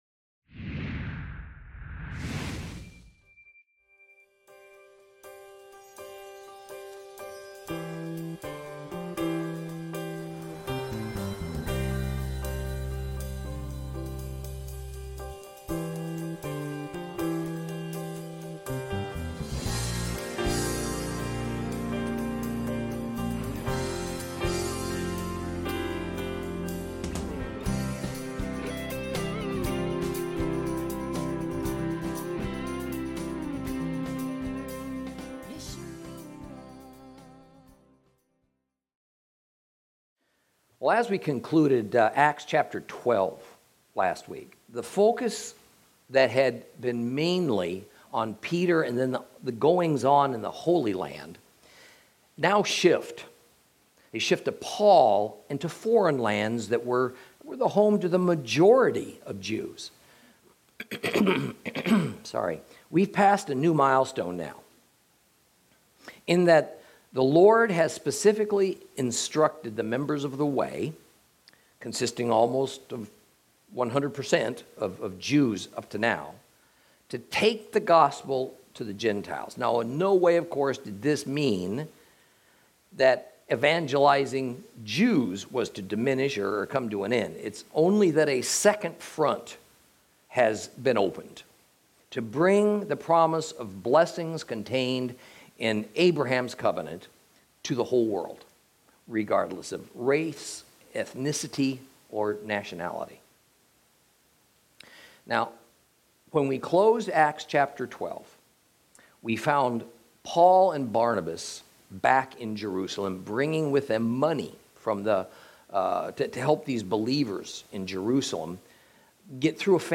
Lesson 29 Ch13 - Torah Class